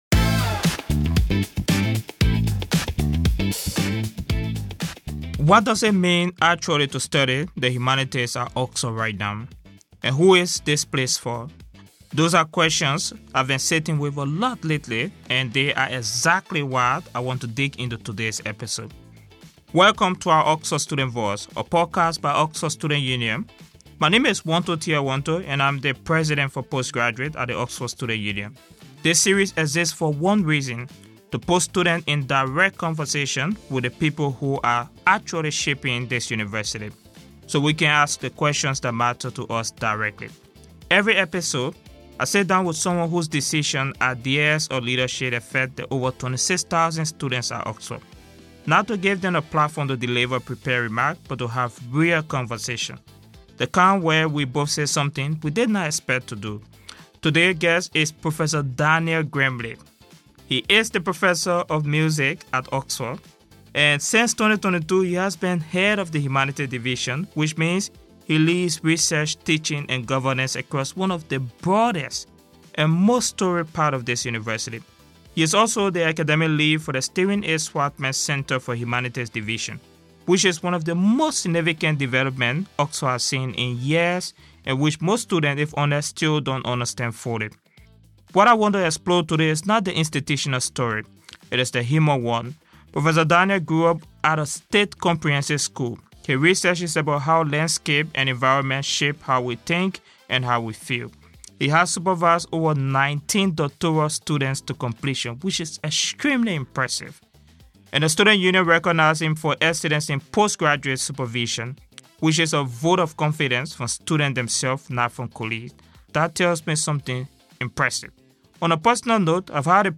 What does it take for students not only to arrive at Oxford, but to feel that they have a place within it, and a role in shaping its future? It is a conversation about tradition and change, about who gets to participate, and about the responsibility institutions carry in opening doors that have too often been closed.